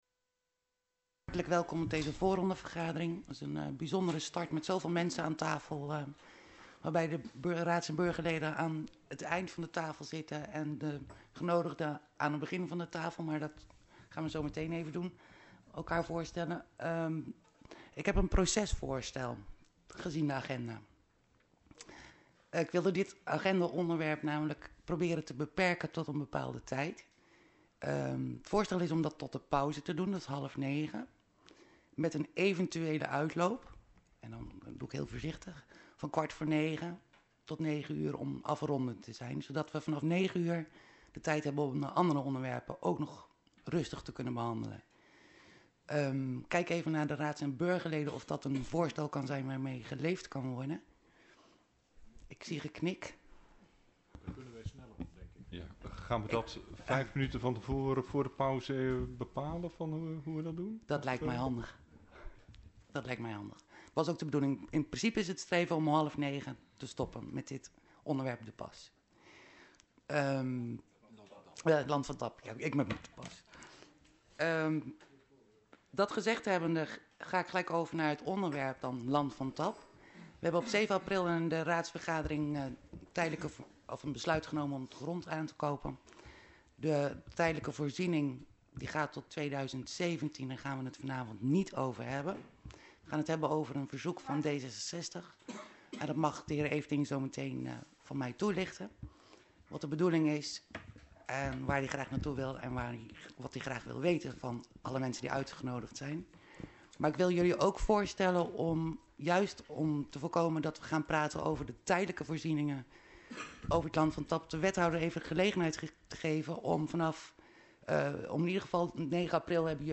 Locatie Hal, gemeentehuis Elst Voorzitter mevr. A.J. Versluis Toelichting RTG ideeen over definitieve invulling Land van Tap Voorbereidende vergadering Vaststellen bestemmingsplan Buitengebied, De Hel 4g, Randwijk.